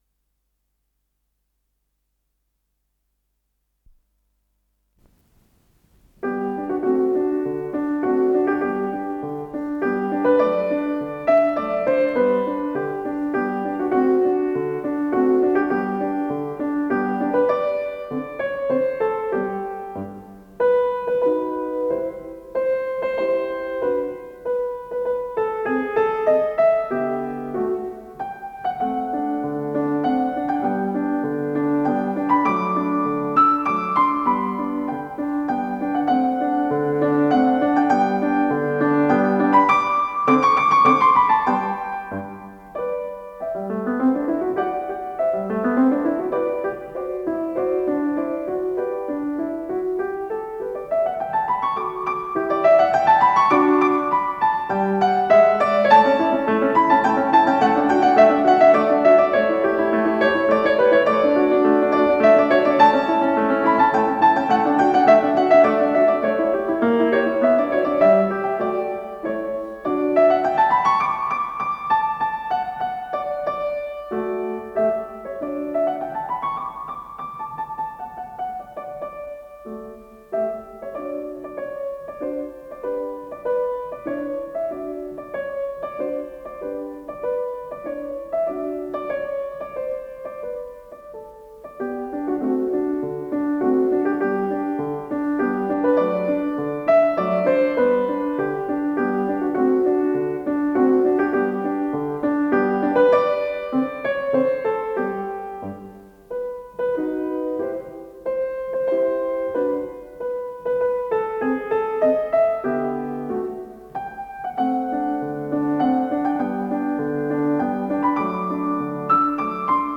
с профессиональной магнитной ленты
ВариантМоно